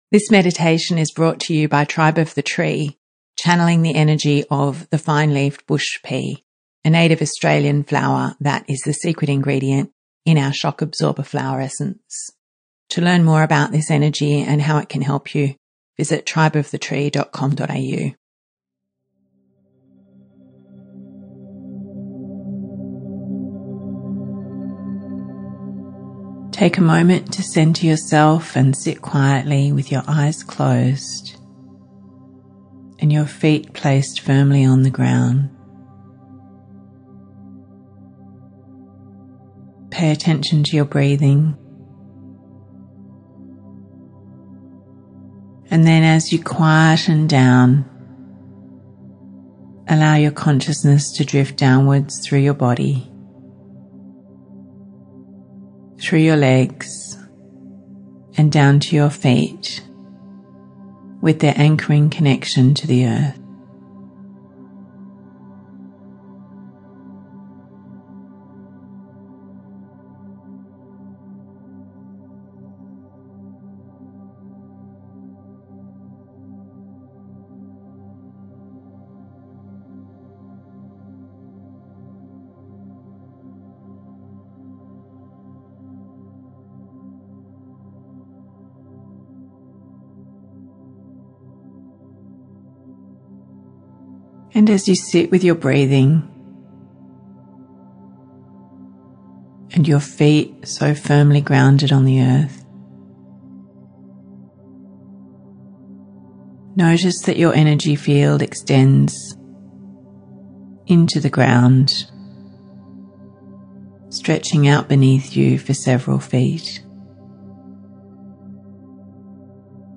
shock-absorber-meditation.mp3